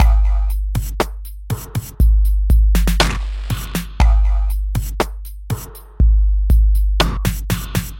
黑暗阴冷的嘻哈鼓循环
Tag: 120 bpm Hip Hop Loops Drum Loops 1.35 MB wav Key : Unknown